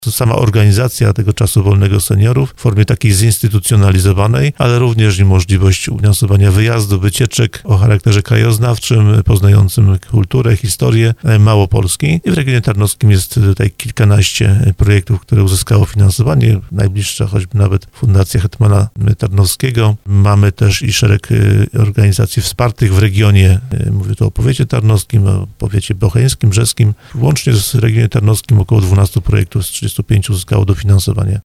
Jak mówi Wojciech Skruch Wiceprzewodniczący Sejmiku Województwa Małopolskiego, stowarzyszenia, fundacje otrzymały dofinansowania w wysokości ponad 2 mln złotych.